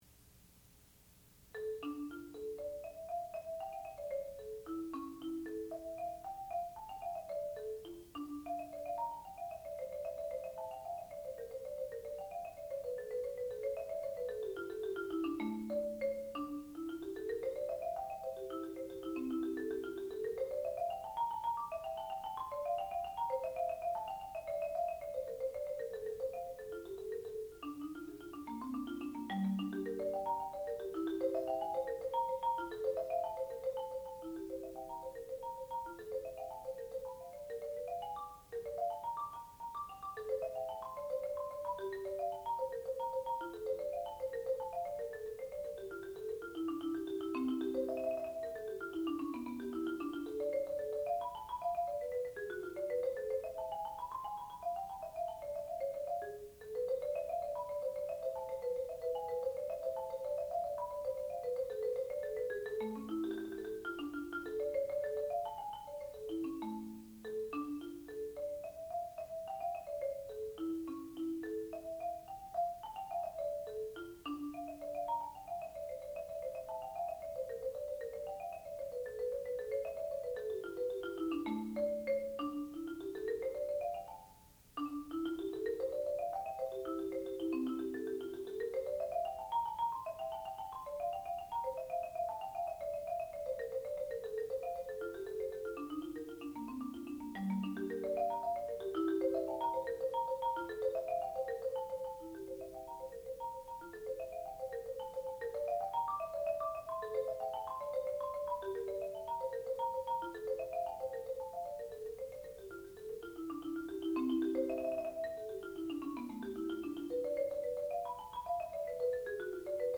sound recording-musical
classical music
Student Recital
percussion